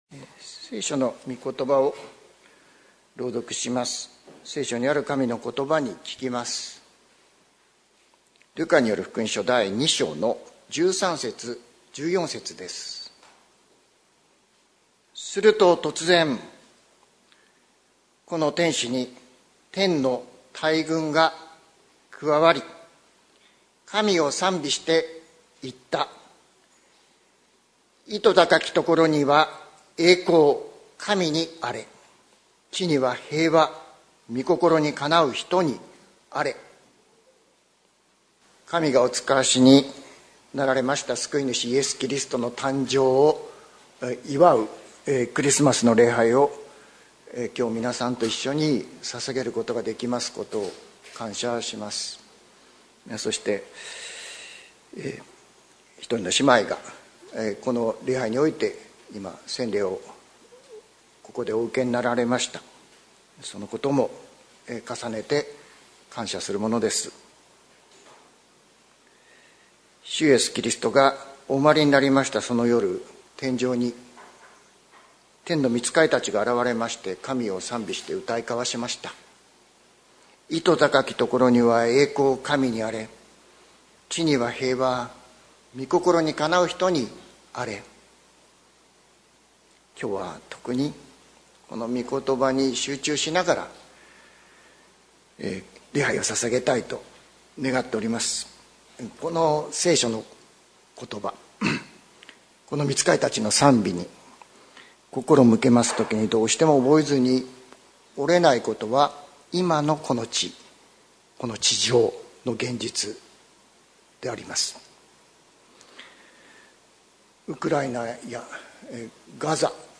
礼拝説教 日曜朝の礼拝